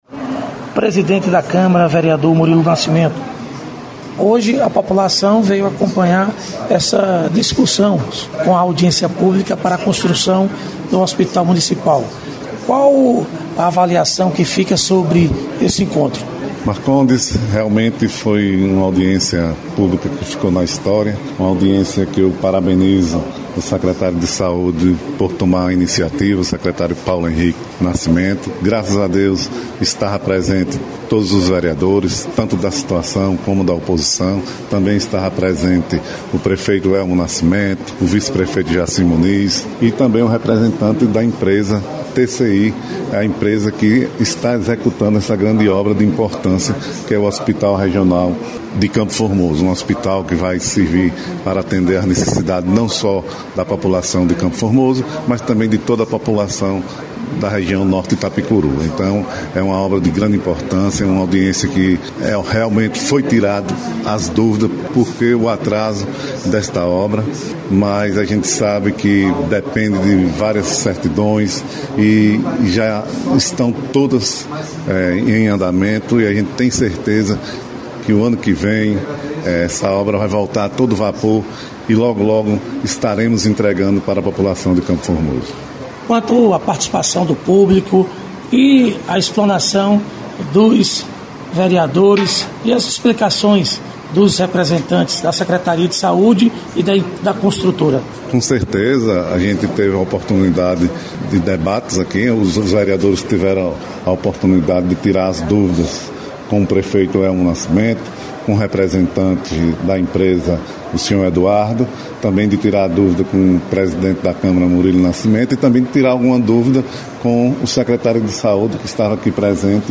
Entrevista com vereadores de Campo Formoso- Audiência pública pra discussão da construção do hospital municipal